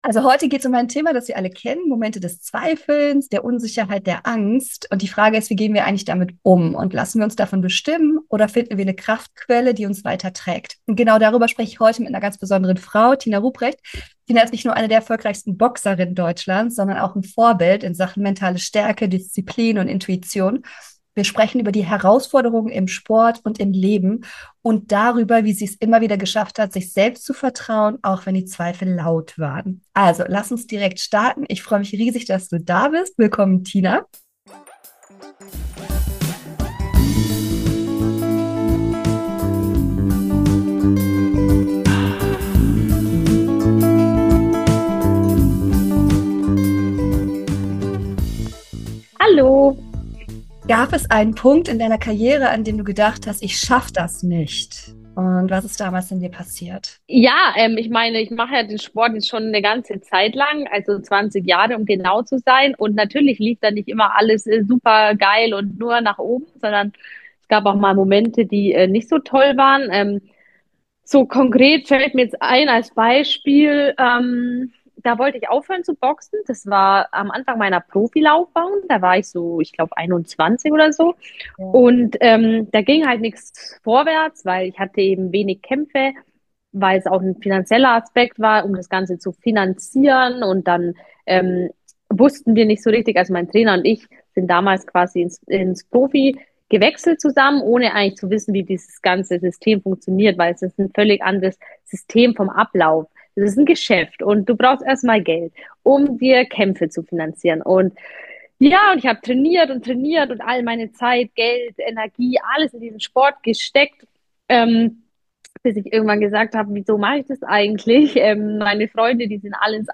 In diesem Gespräch erzählt sie:  Wie sie mit den schwierigsten Momenten ihrer Karriere umgegangen ist.